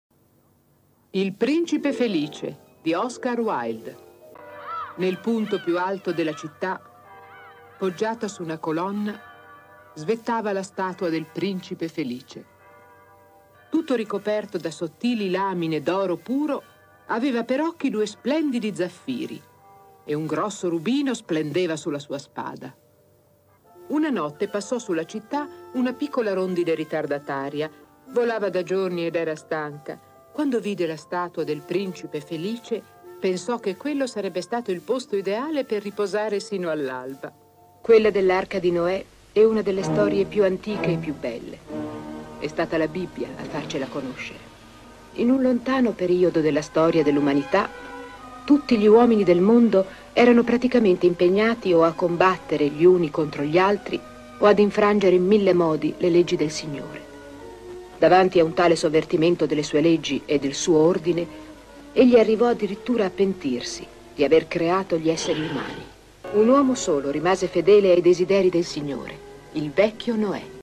nel cartone animato "Fiabe... così", in cui è la voce narrante.
• Voce narrante in "Fiabe... così"